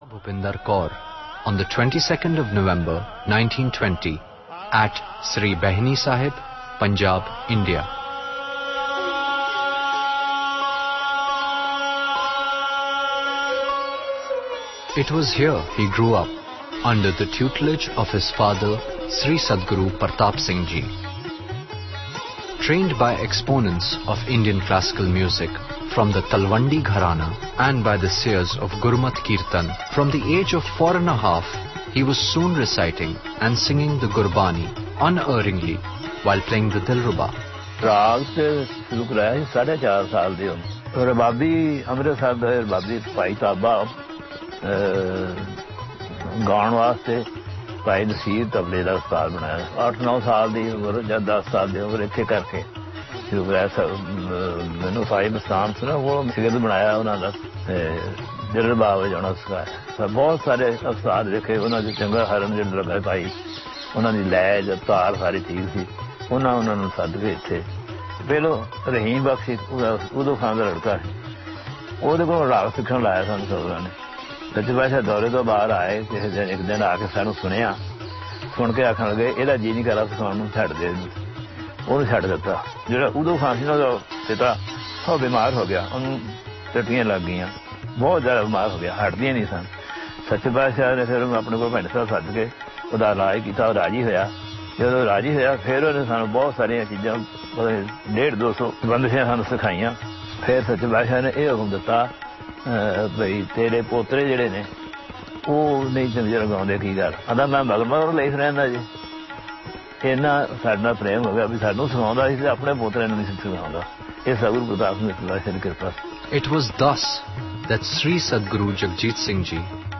In the way of a musical evening, Indian classical music tributes were paid by the Hazuri raagis from Sri Bhaini Sahib
vocal
sitar
tabla
Sitar Recital